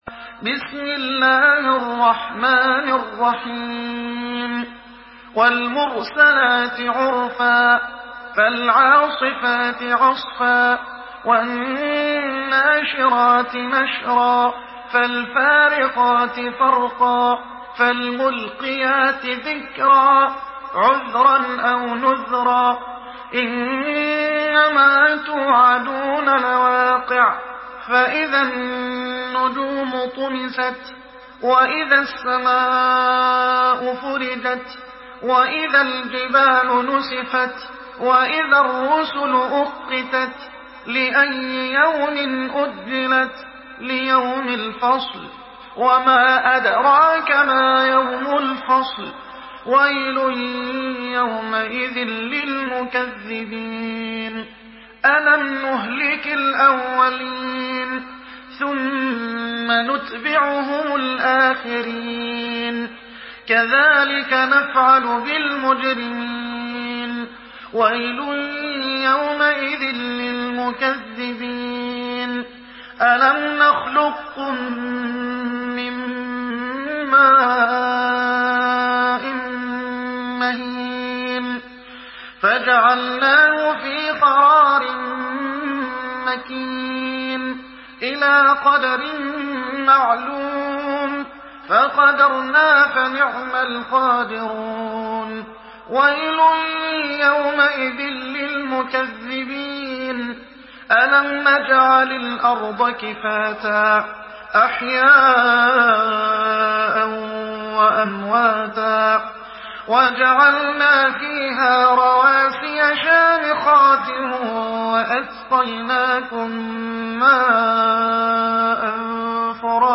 Hafs An Asim narration
Murattal Hafs An Asim